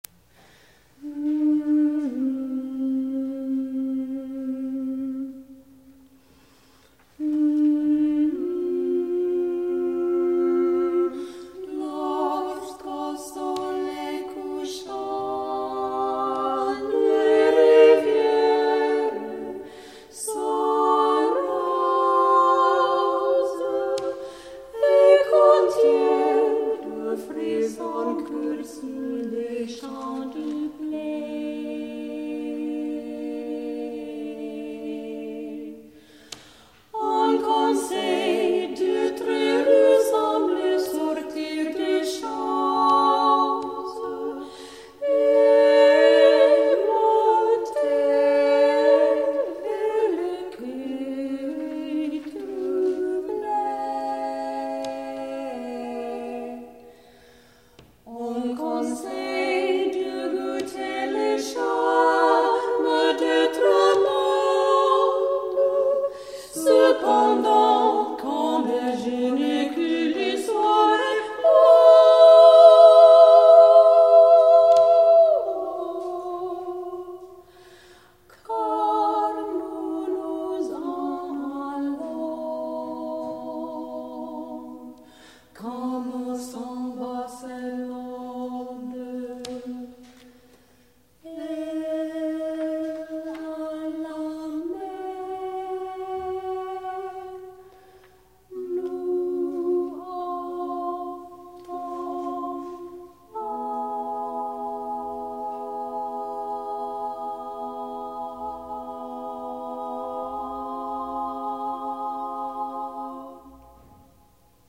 Songs and Clips - 2nd Half - Vocal
TRIO Section: